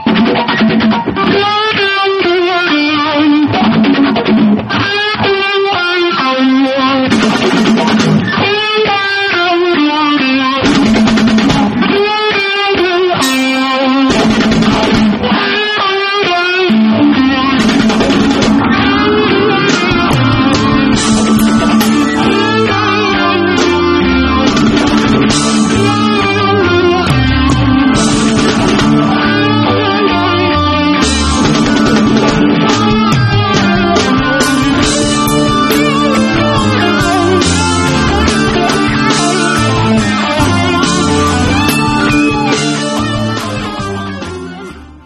Funk
Fusion
Instrumental
Psychedelic
Rock